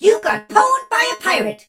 darryl_kill_vo_01.ogg